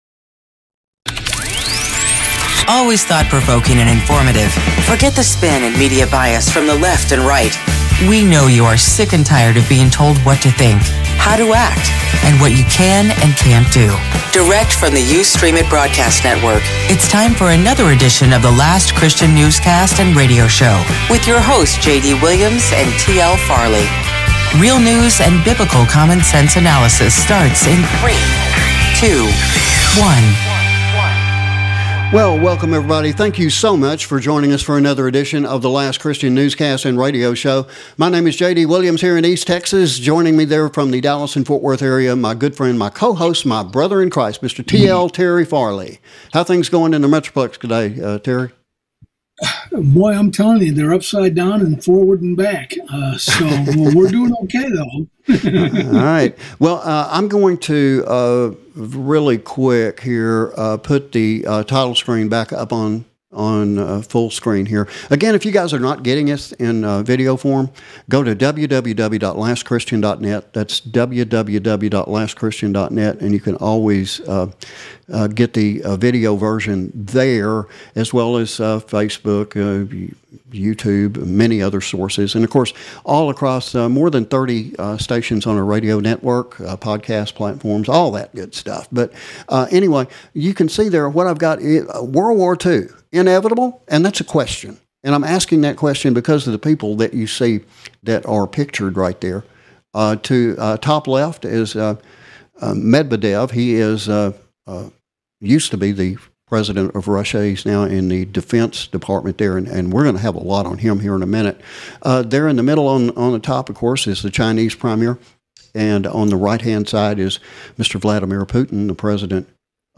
In this edition of the Newscast we'll examine the threats facing the World in the form of Nuclear War. And we will also continue our Series focusing on the very first Book of the Bible, Genesis.